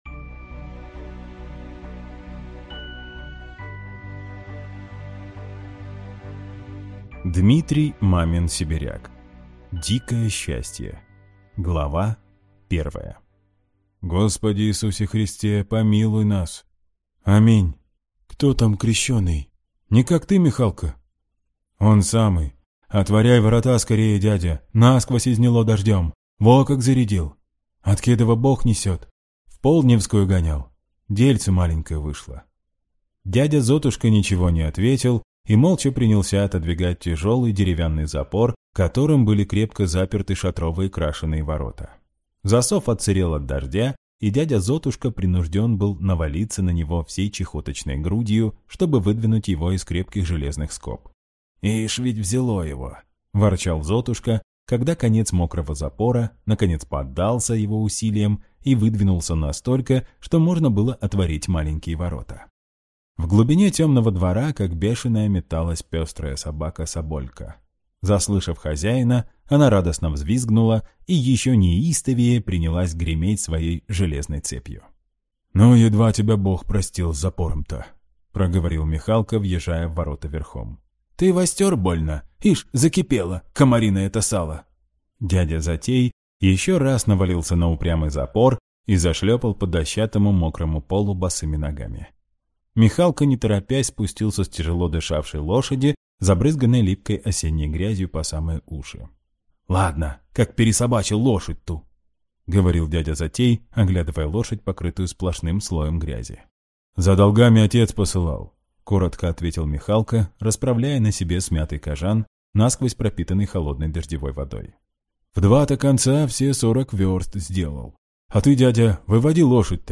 Аудиокнига Дикое счастье | Библиотека аудиокниг